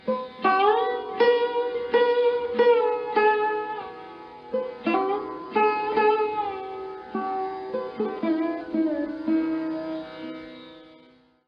sarod